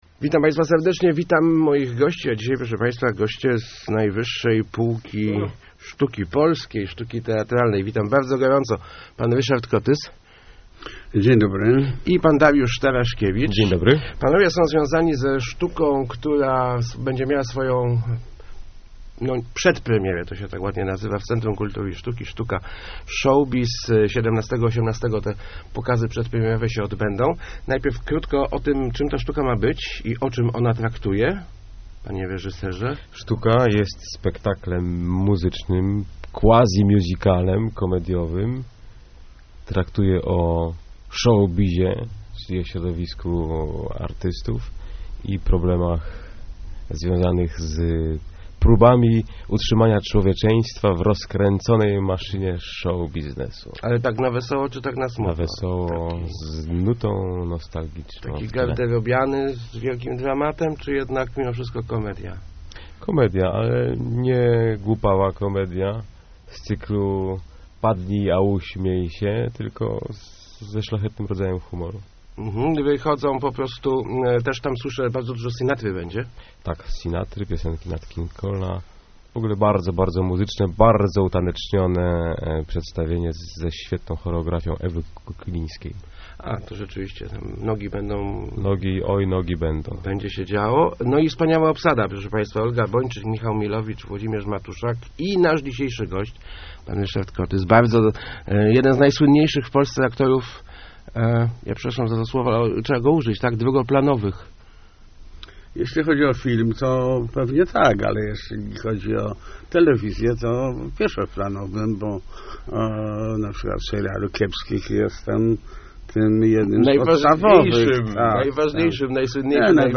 Nie obrażam się, gdy ktoś zwraca się do mnie "panie Paździoch" - mówił w Rozmowach Elki aktor Ryszard Kotys. Gwiazda "Świata według Kiepskich" występuje w Lesznie w spektaklu "Showbizz", przygotowywanym przez Centrum Kultury i Sztuki.